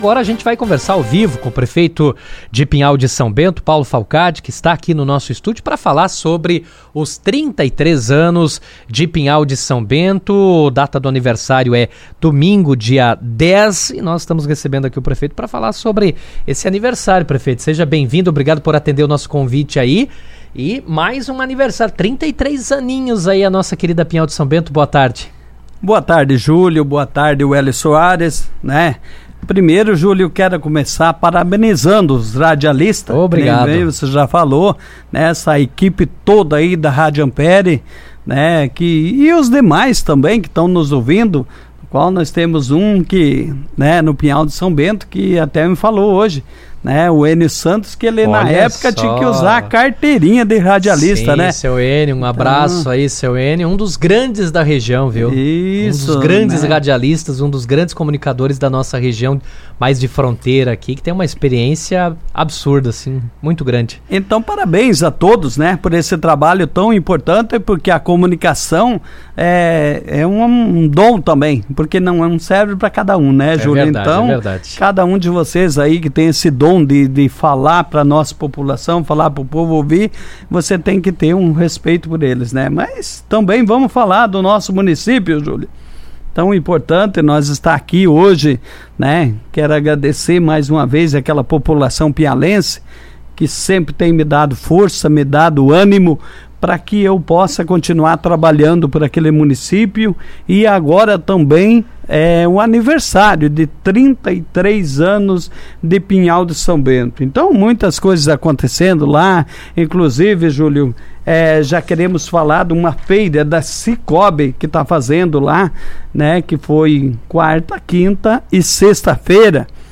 Em entrevista ao Jornal RA 2ª Edição desta quinta-feira, 07, o prefeito Paulo Falcade deu detalhes da programação e destacou a importância da data para o município.